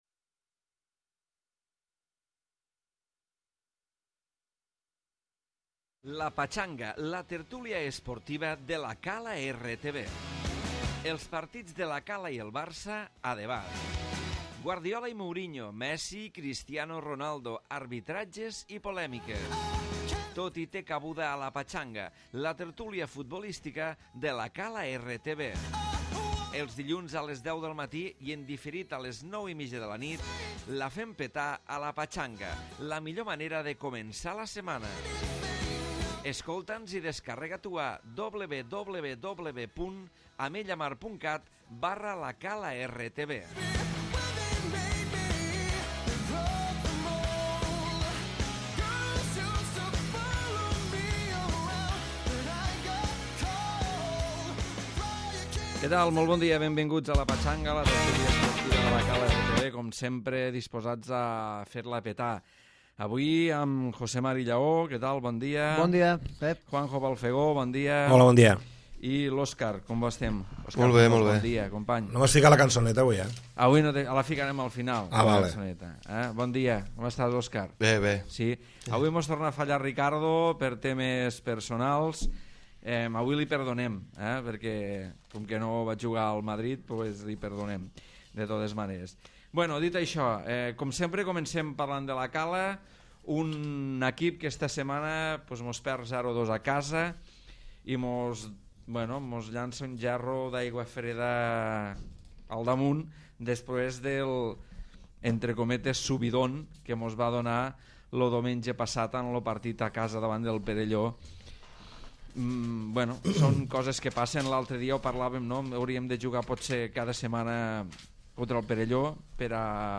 Avui a La Patxaga, tertúlia futbolistica amb el partit del 1er equip de La Cala com a tema inicial, i el Barça I EL Madrid com sempre, amb Messi, Cristiano Ronaldo i la resta de temes d'actuallitat.